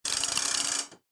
telephone_handle2.ogg